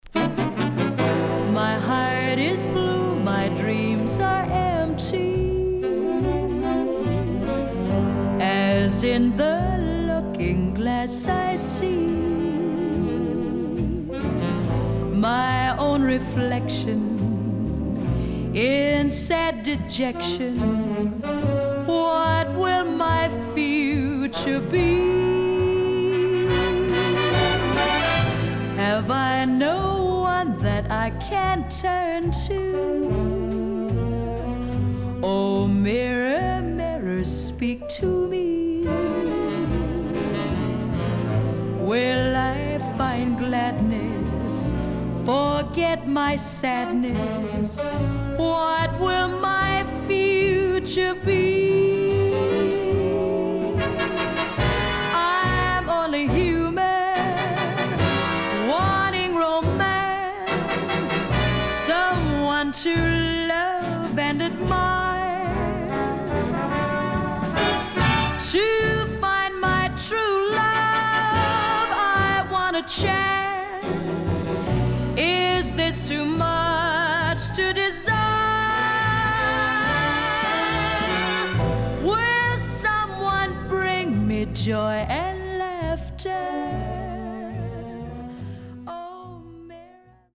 HOME > SOUL / OTHERS